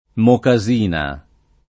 [ moka @& na ]